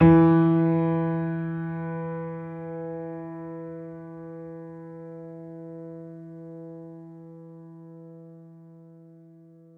piano-sounds-dev
Vintage_Upright
e2.mp3